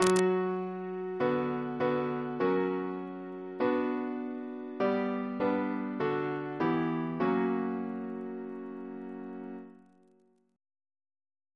Single chant in B minor Composer: C. Hylton Stewart (1884-1932), Organist of Rochester and Chester Cathedrals, and St. George's, Windsor Reference psalters: ACB: 346; ACP: 300 345; RSCM: 208